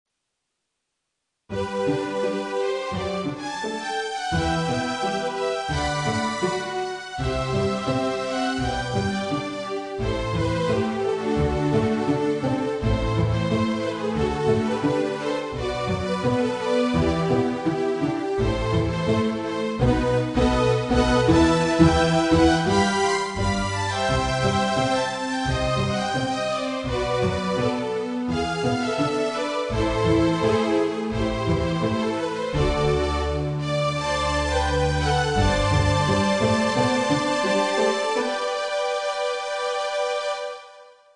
Strings (Format:mp3,56kBit/s,24kHz,Stereo Size:282kB)